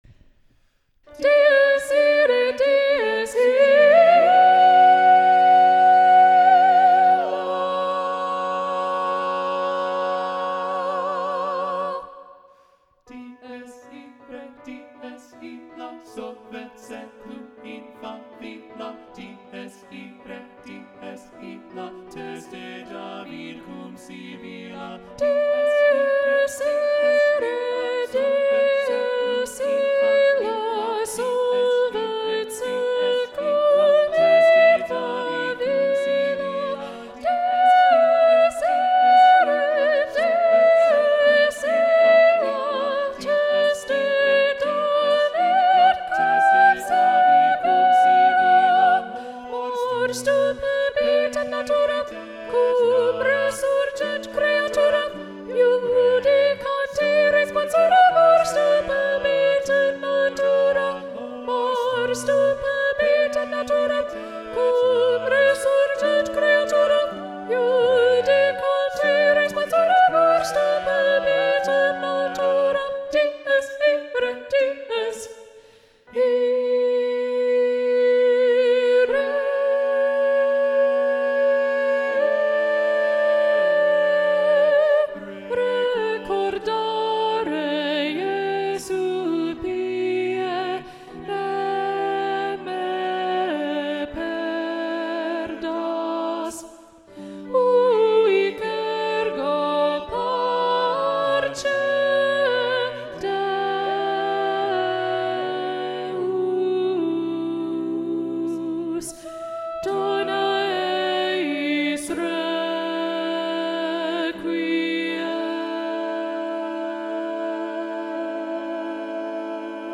- Œuvre pour chœur à 8 voix mixtes (SSAATTBB)
SATB Soprano 1 Predominant